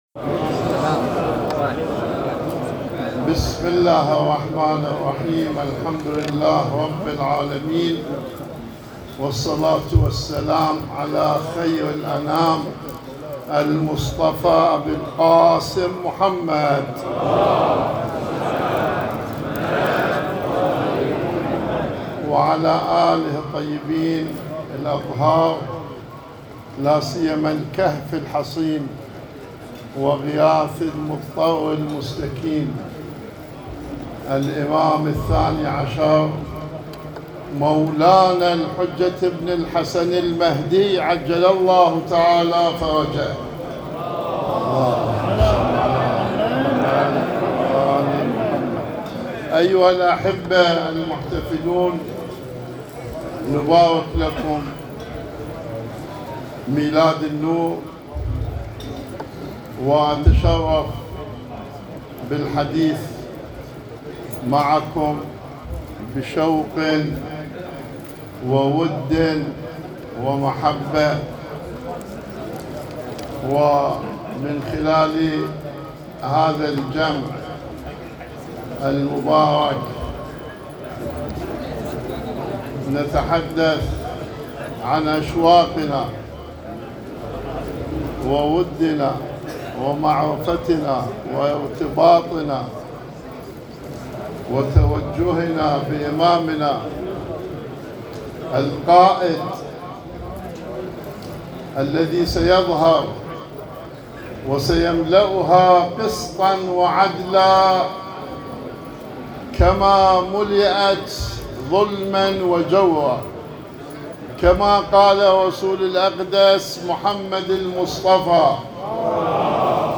كلمة سماحة آية الله الكعبي فی التجمع المهدوی – مرکز علمی فرهنگی امام حسین (ع)